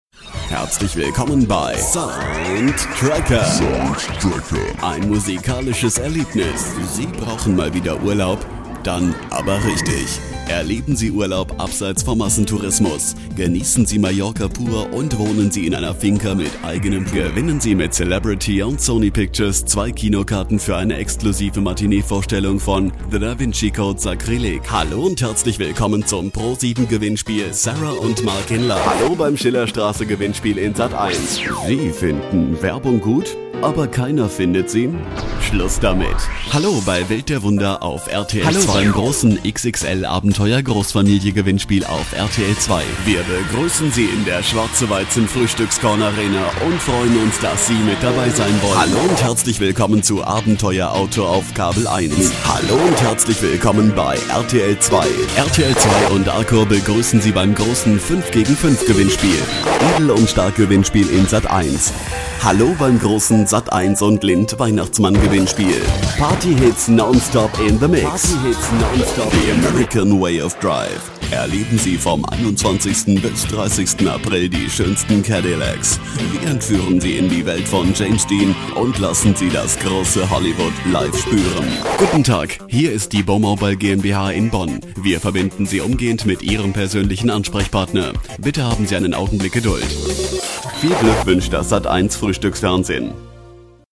Sprecher deutsch.
Kein Dialekt
voice over talent german